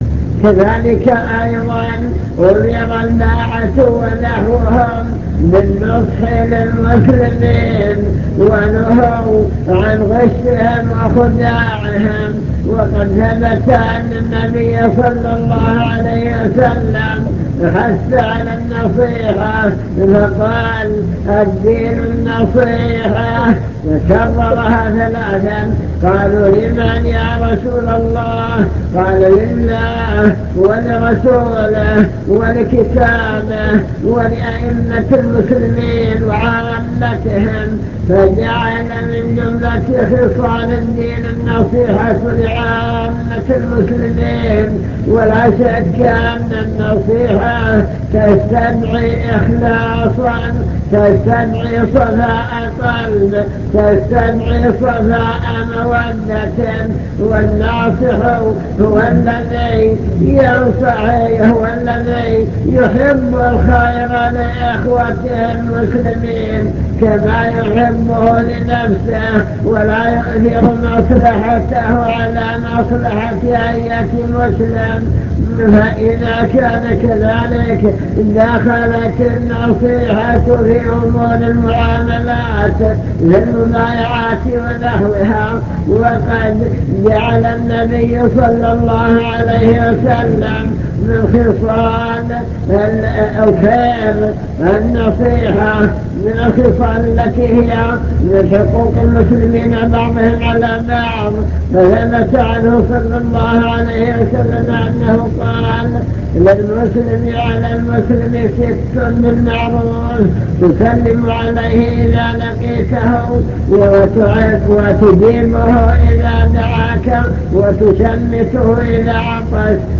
المكتبة الصوتية  تسجيلات - محاضرات ودروس  الربا وما يتعلق به من أحكام